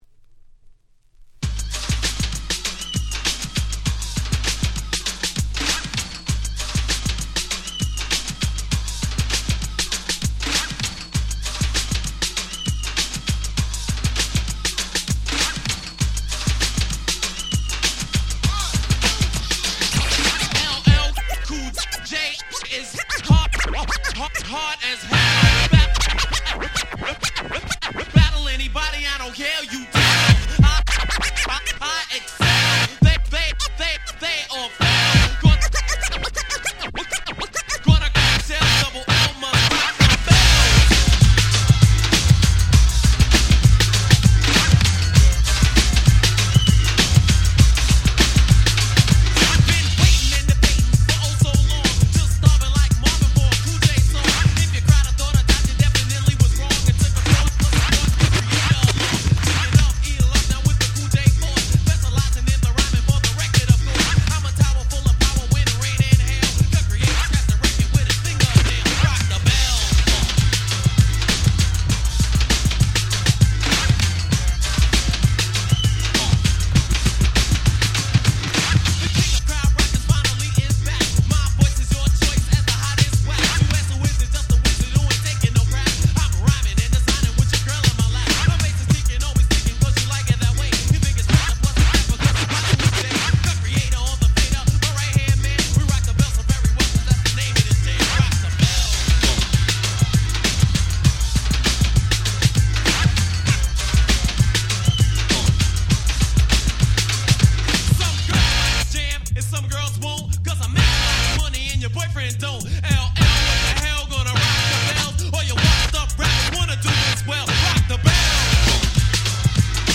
【Media】Vinyl 12'' Single